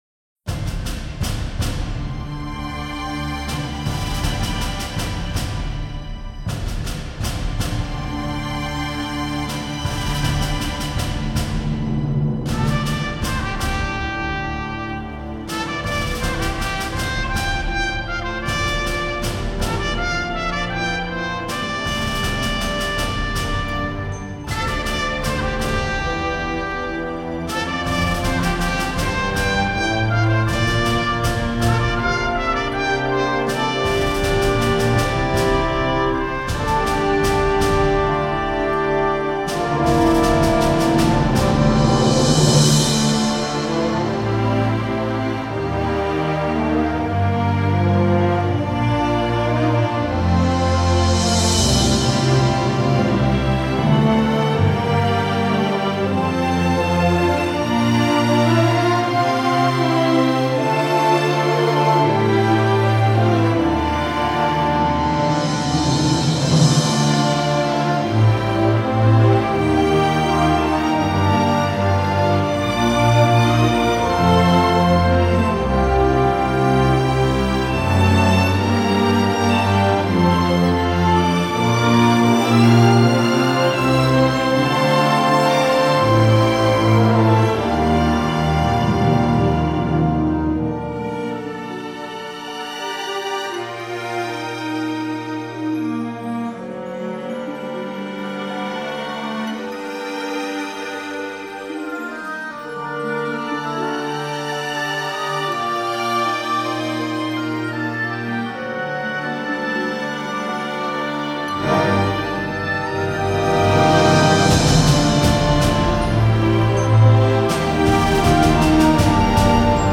national anthem